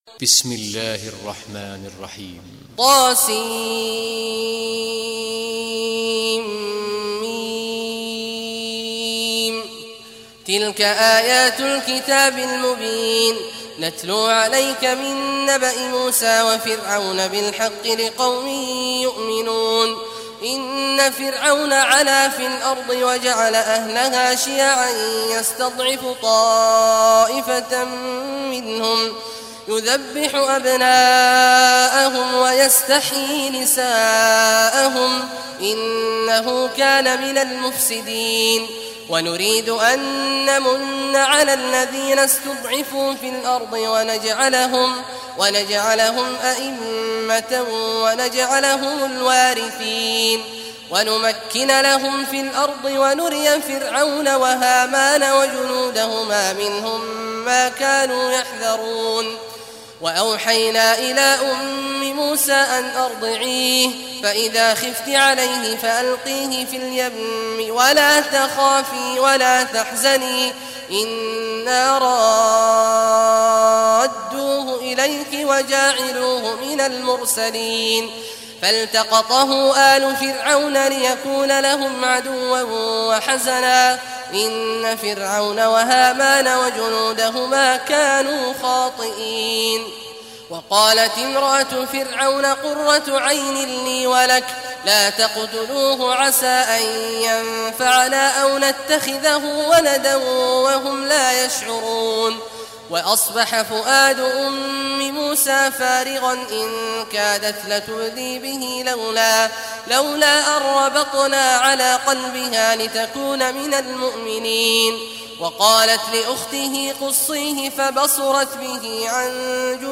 Surah Al-Qasas Recitation by Sheikh al Juhany
Surah Al-Qasas, listen or play online mp3 tilawat/ recitation in Arabic in the beautiful voice of Sheikh Abdullah Awad al Juhany.